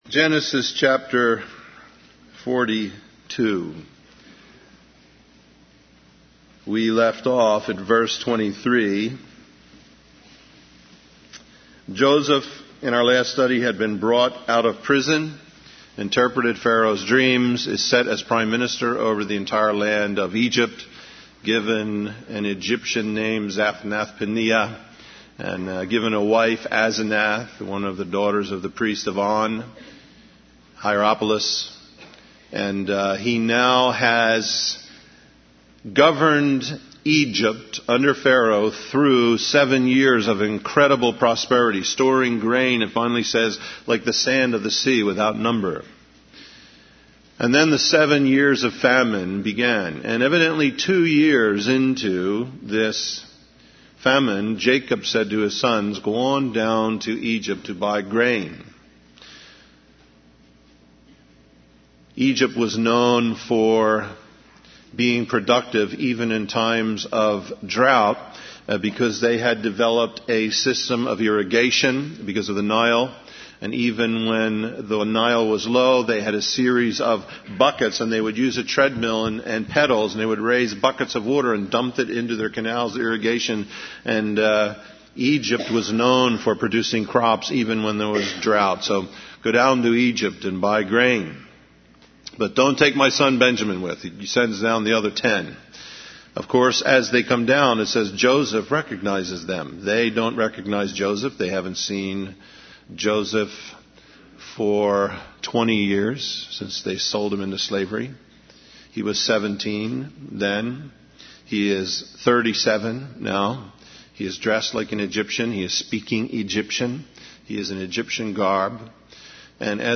In this sermon, the speaker focuses on the story of Joseph and his brothers in the book of Genesis. He highlights the moment when Joseph reveals his true identity to his brothers and forgives them for selling him into slavery. The speaker emphasizes the importance of forgiveness and how Joseph was able to see God's hand in his circumstances.